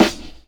HipHop Snare.wav